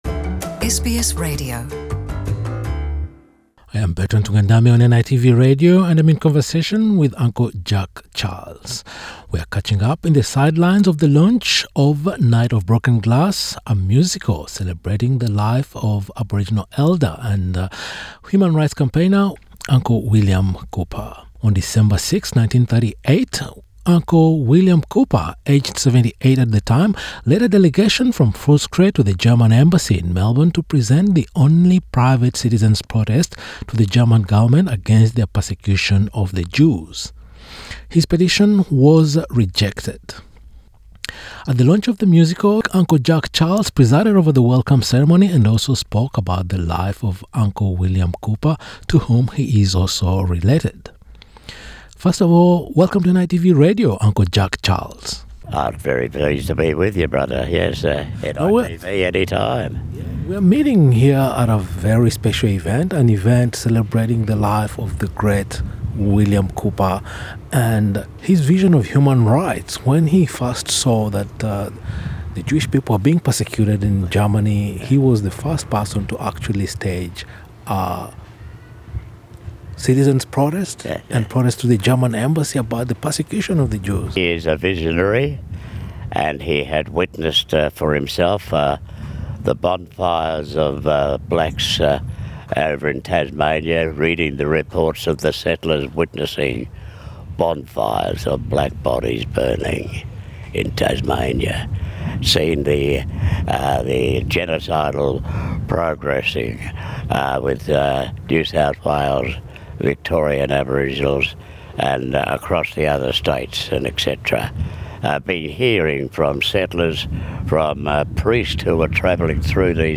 Yarn with Uncle Jack Charles at the launch of Night of Broken Glass, a musical celebrating William Cooper’s act on December 6, 1938 when he petitioned the German government for its persecution of Jews - the only private citizen to do so.